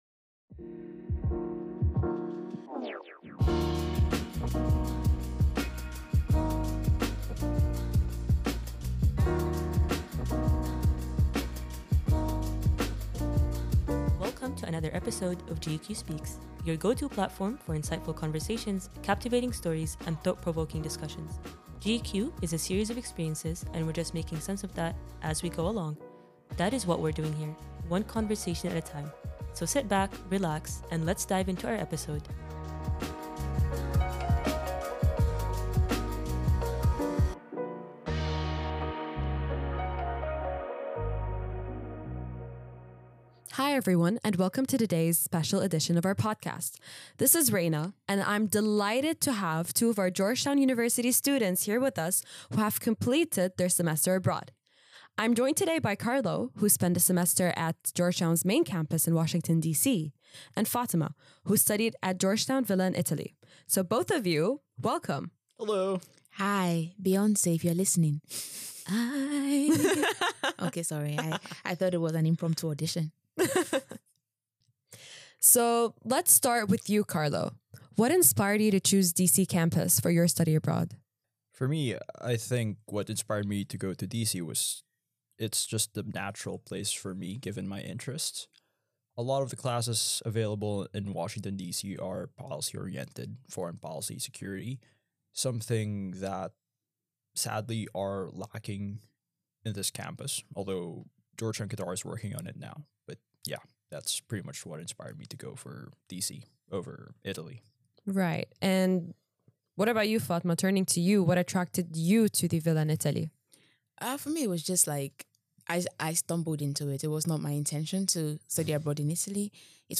In this episode, podcast hosts and fellow GU-Qatar students share their experiences studying abroad at other GU campuses in Florence and D.C.